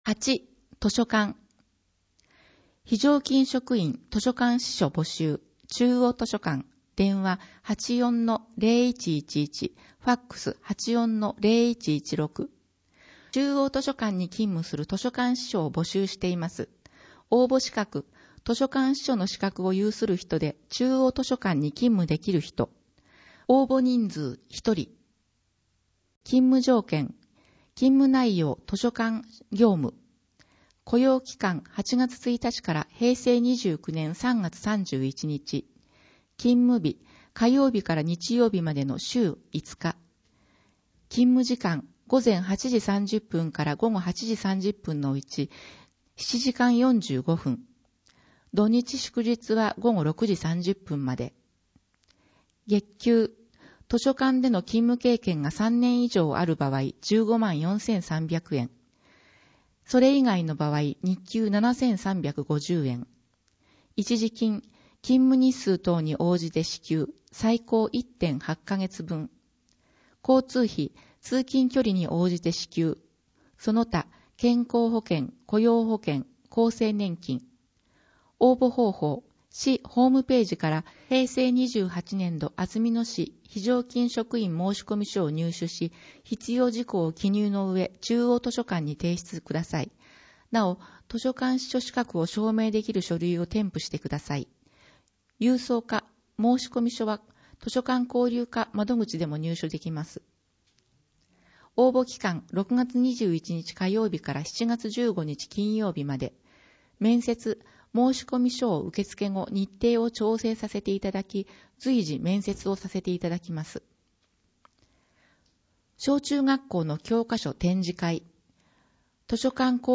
「広報あづみの」を音声でご利用いただけます。